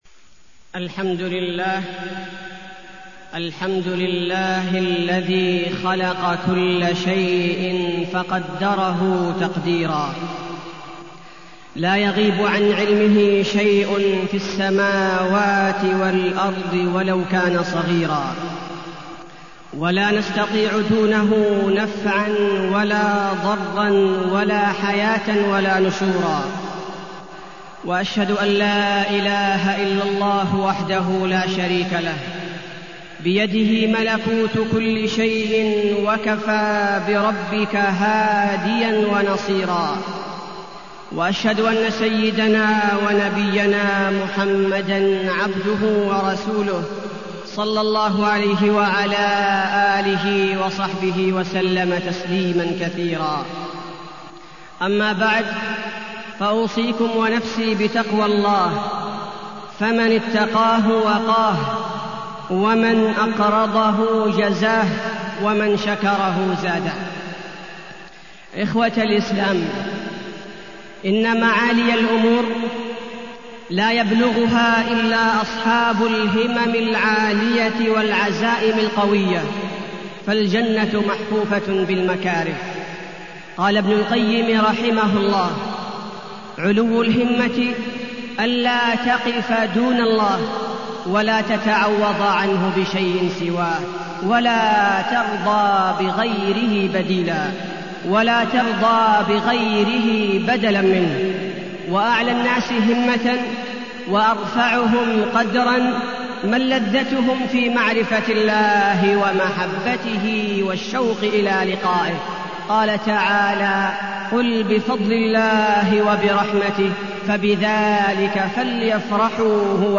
تاريخ النشر ٢٧ صفر ١٤٢٠ هـ المكان: المسجد النبوي الشيخ: فضيلة الشيخ عبدالباري الثبيتي فضيلة الشيخ عبدالباري الثبيتي علو الهمة